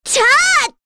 Kirze-Vox_Casting3_kr.wav